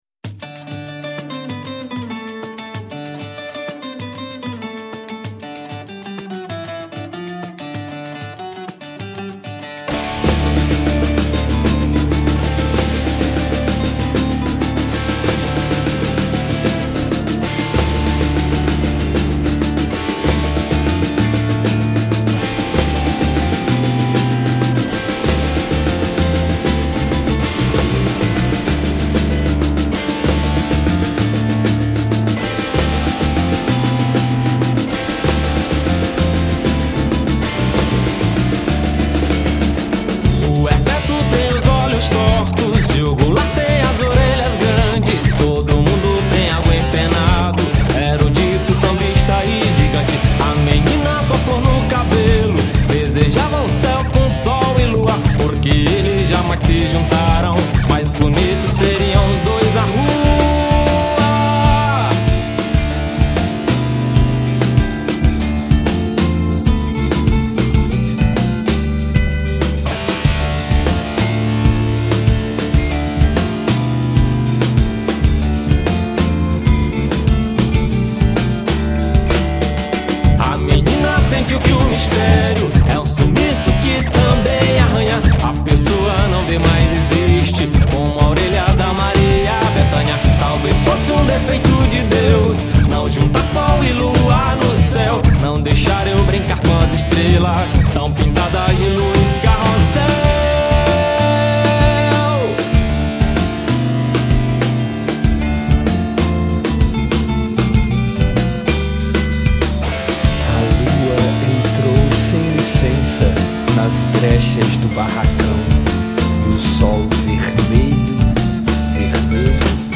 1913   03:59:00   Faixa:     Rock Nacional